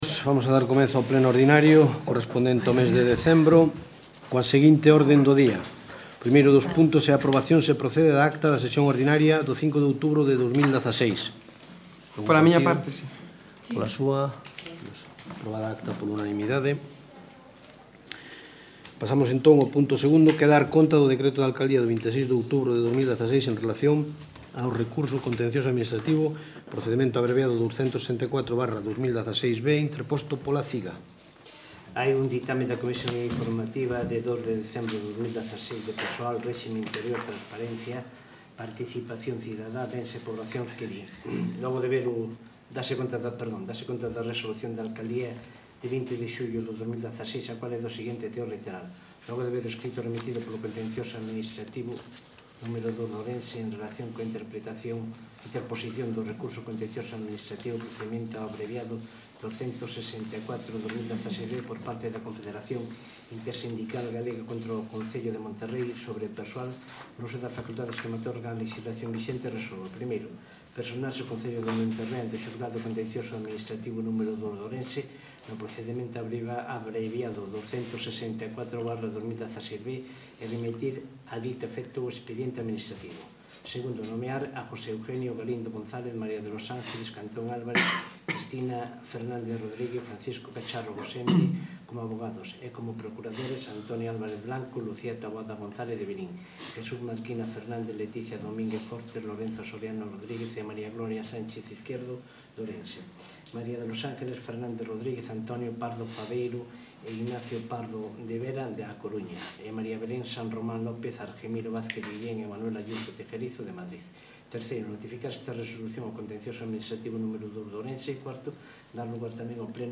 PLENO ORDINARIO 07 DE DECEMBRO DE 2016